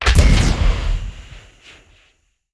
SOUNDS / WEAPONS
fire_mine_regular.wav